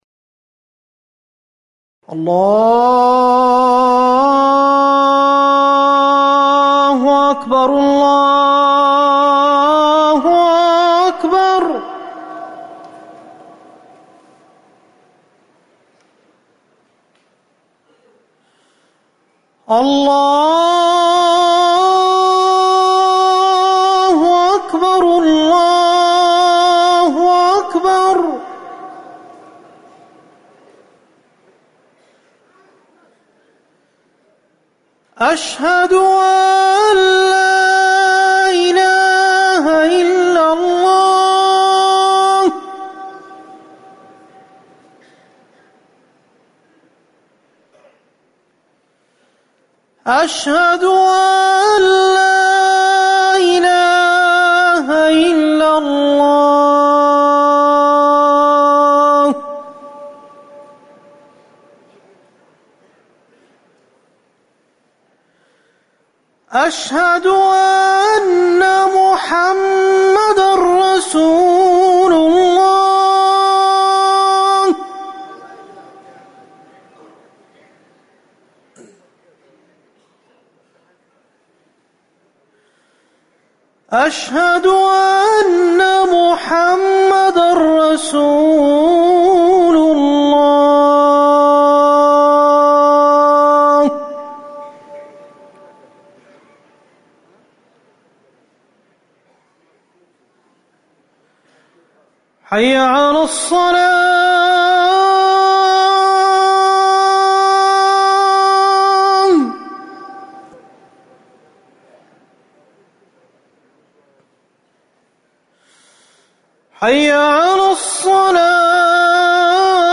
أذان الظهر
المكان: المسجد النبوي